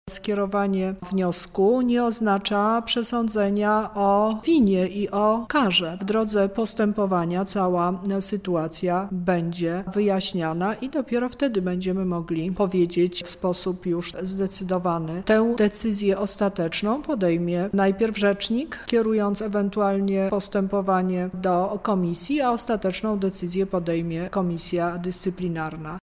nauczyciele – mówi Teresa Misiuk Lubelska Kurator Oświaty